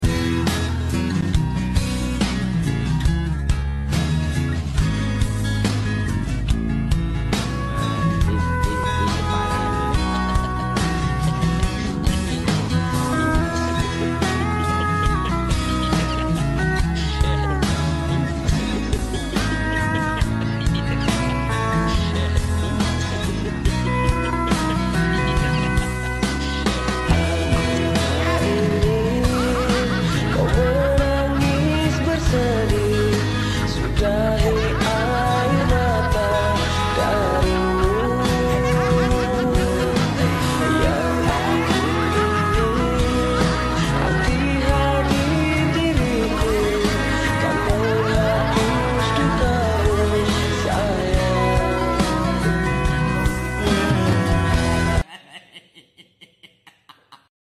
Live Streaming Karaoke